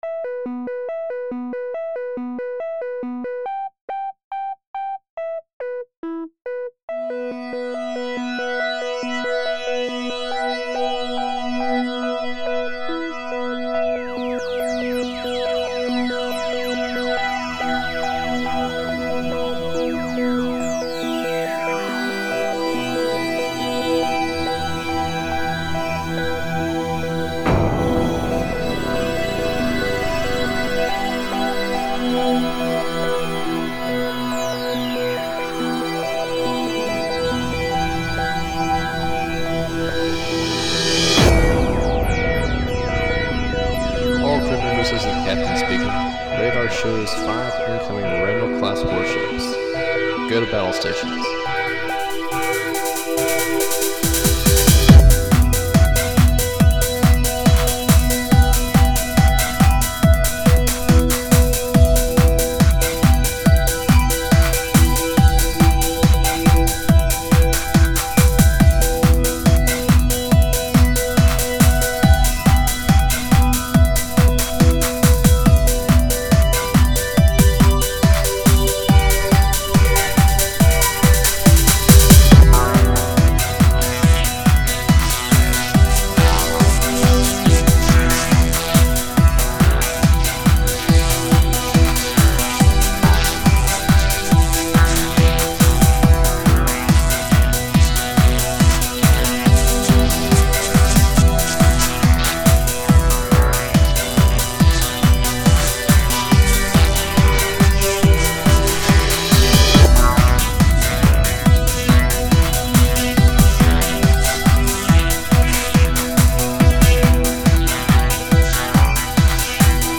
Music / Techno
:D Pretty cool sound. I could almost imagine this as the theme song to some kind of cyberpunk drama tv show.